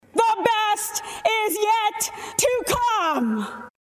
kimberly-guilfoyle-full-rnc-speech-abc7_mdgwkYX.mp3